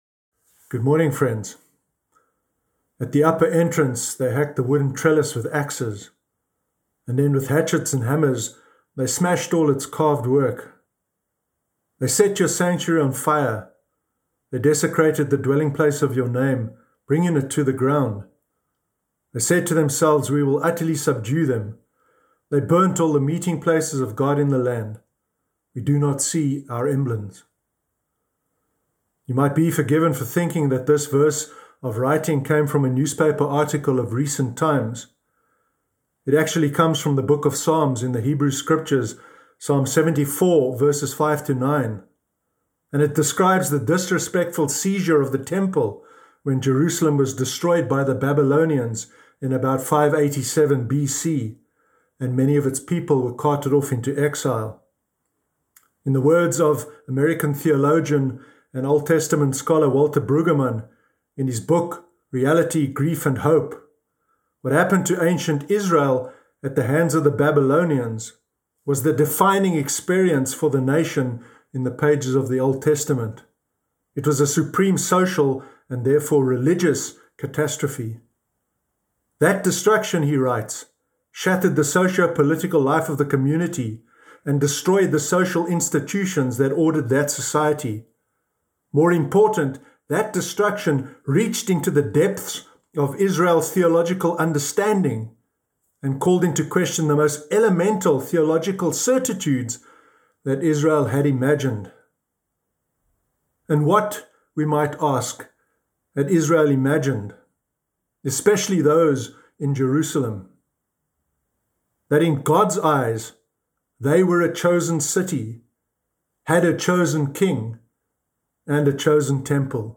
Sermon Sunday 24 January 2021